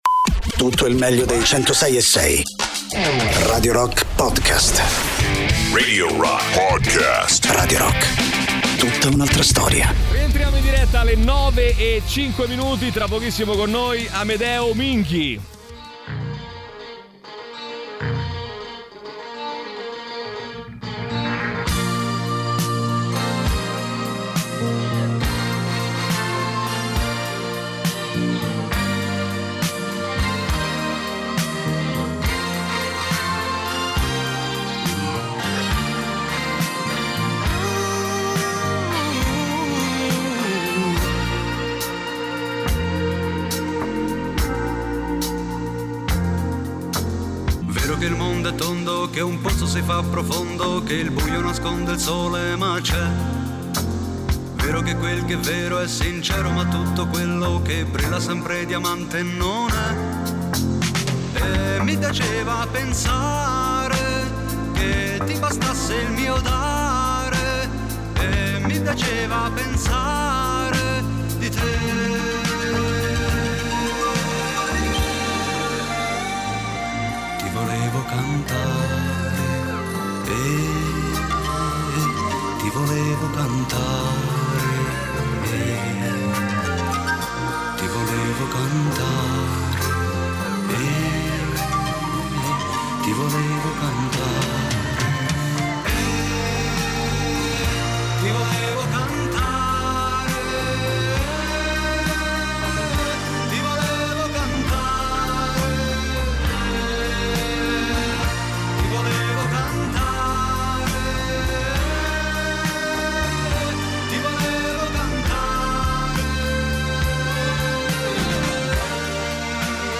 Interviste: Amedeo Minghi (23-04-25)